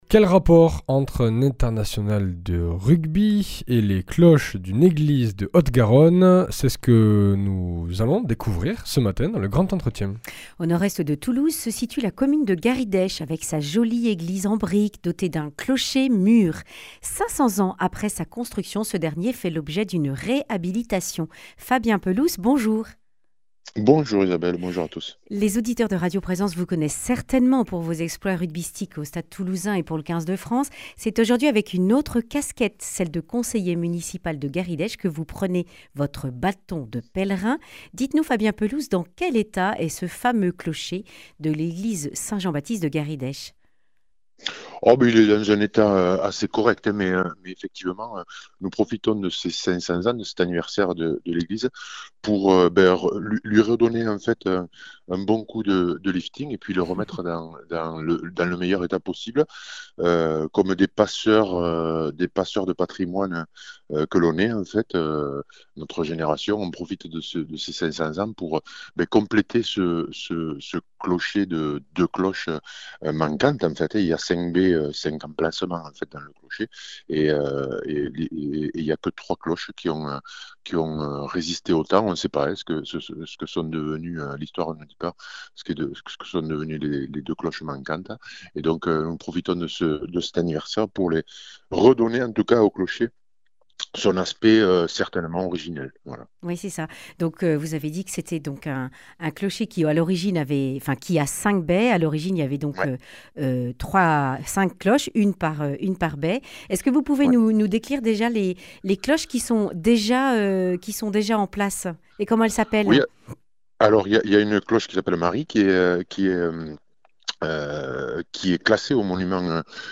Deux nouvelles cloches pour l’église de Garidech vont être fondues le 14 juin puis bénies et installées le 21 septembre. Fabien Pelous, international de rugby et conseiller municipal, détaille le projet qui fédère tous les habitants.
Le grand entretien